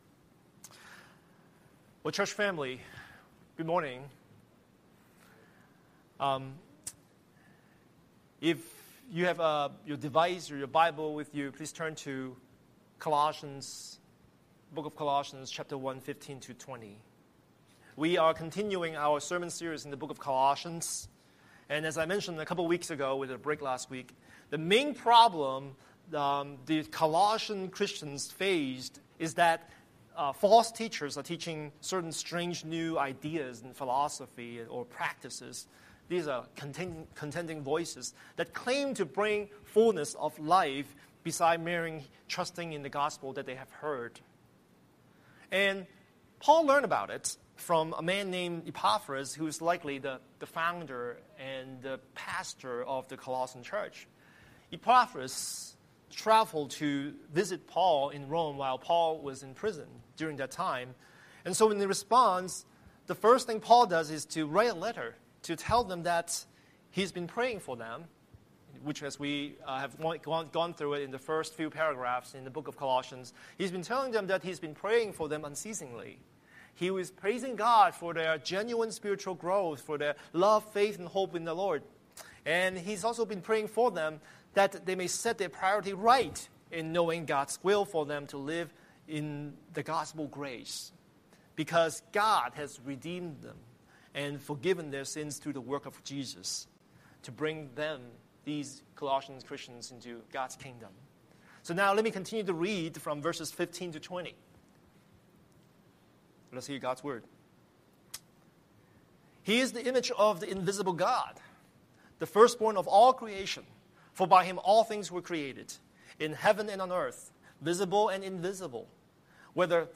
Scripture: Colossians 1:15-20 Series: Sunday Sermon